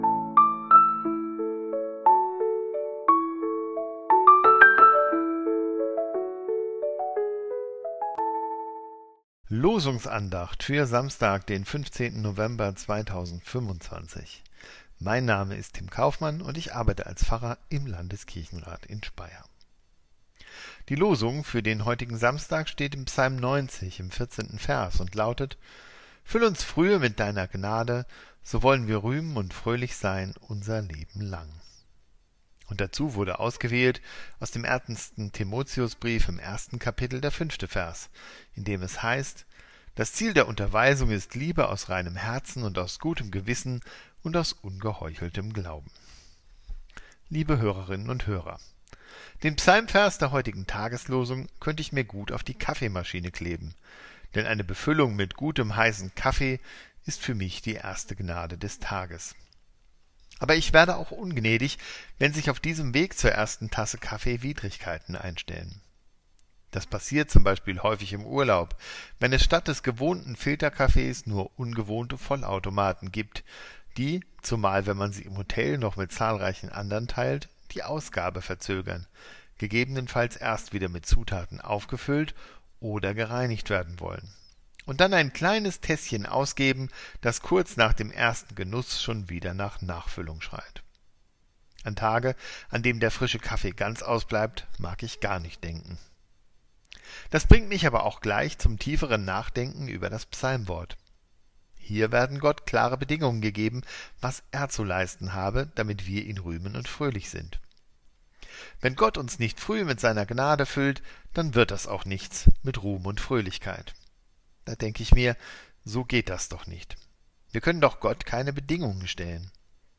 Losungsandacht für Samstag, 15.11.2025 – Prot.